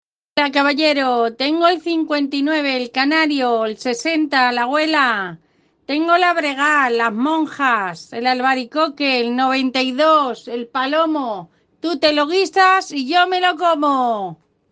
“Señorita, caballero, tengo el 59, el canario; el 60, la abuela; las monjas, el albaricoque; el 92, el palomo… tú te lo guisas y yo me lo como”, nos regala su cántico retahíla,
con apostilla incluida. formato MP3 audio(0,11 MB)